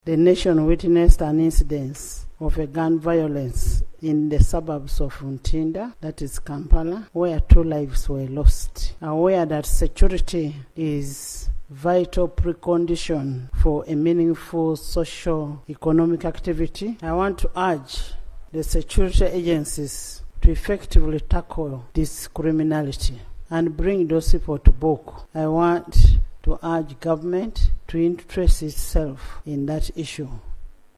Speaker Among chaired the House on Tuesday, 26 November 2024